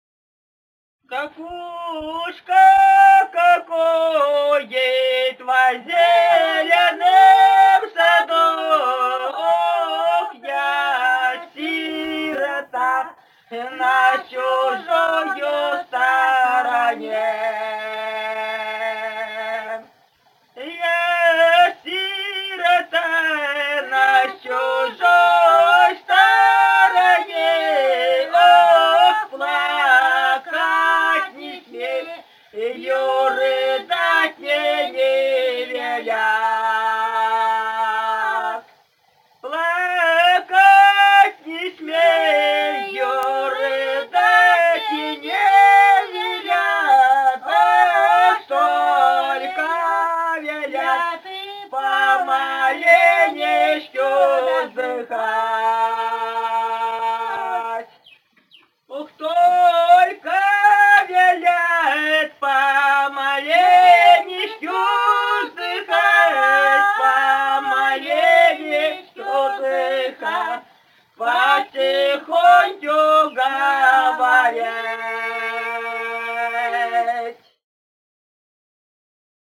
д. Язовая Катон-Карагайского р-на Восточно-Казахстанской обл.